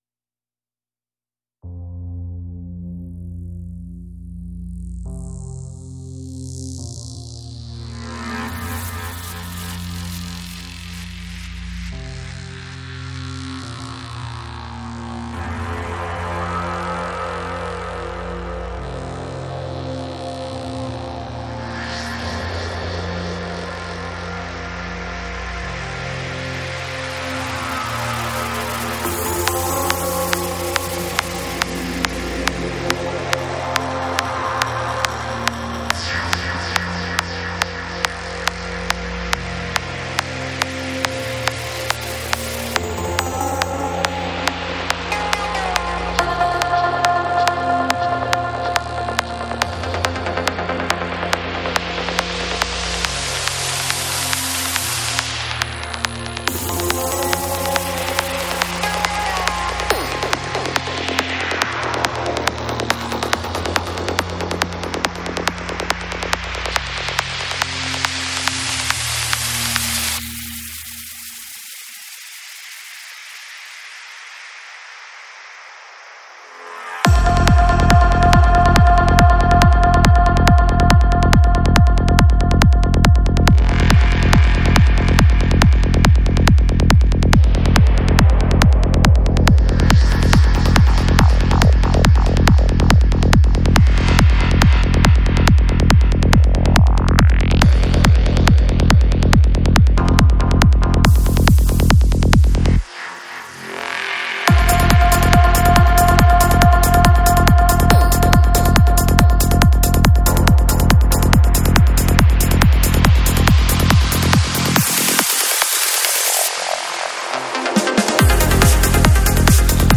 Жанр: Trance
Psy-Trance